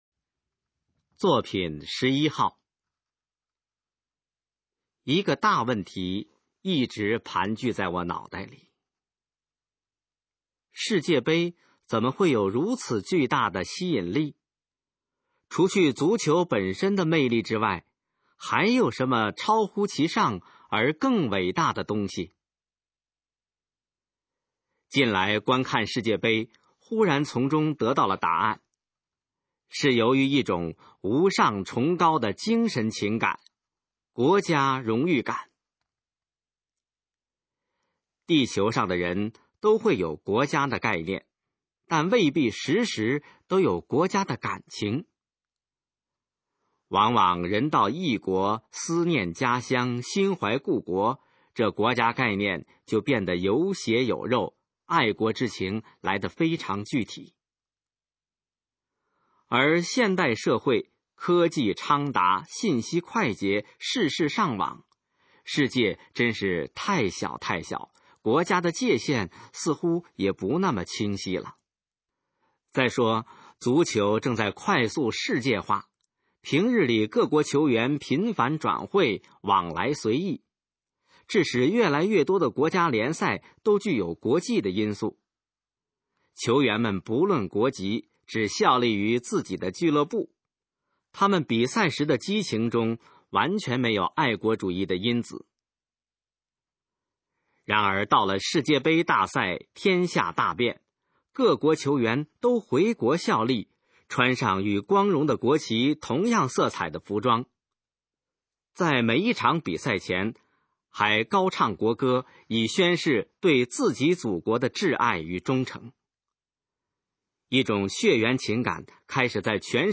《国家荣誉感》示范朗读_水平测试（等级考试）用60篇朗读作品范读